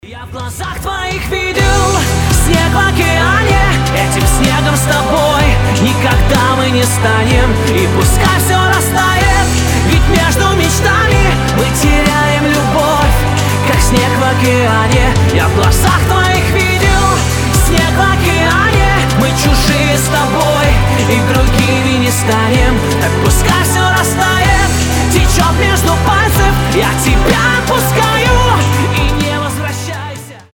Танцевальные рингтоны
Мужской голос
Поп